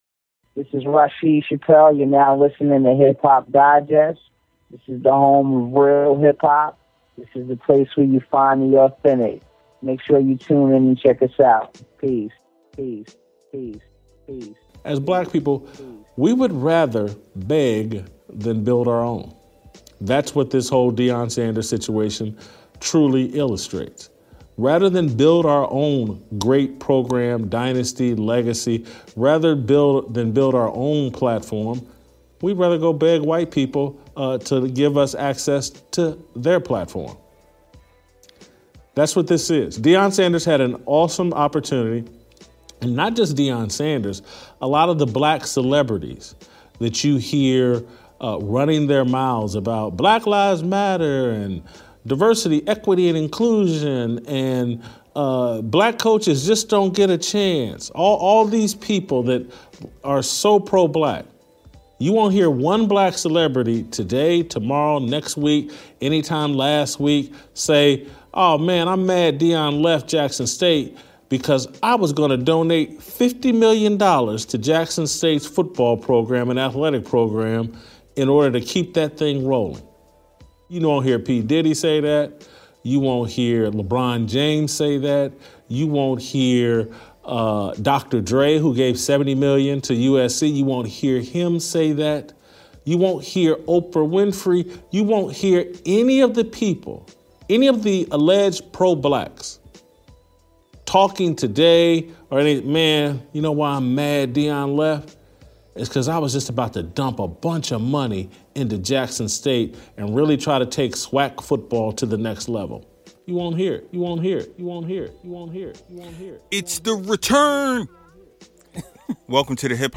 Background Music